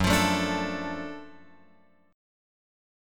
F# Minor Major 7th Sharp 5th